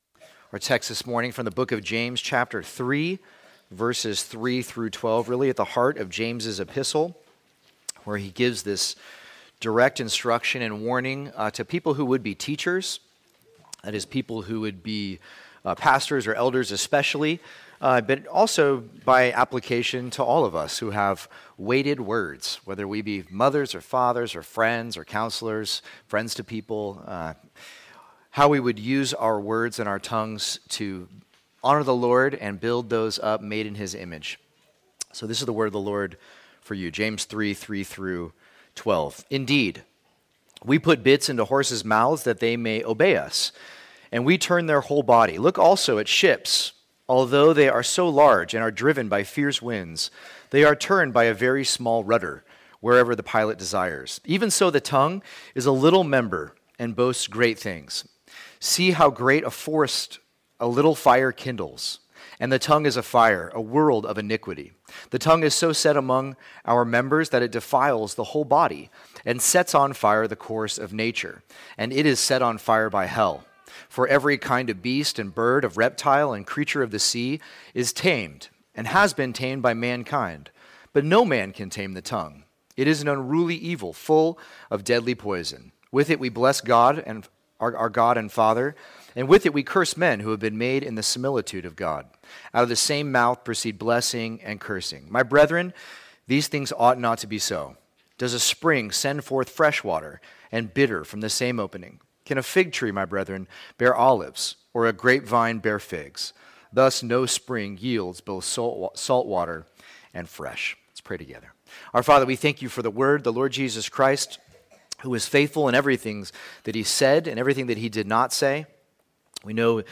2024 The Untamed Tongue Preacher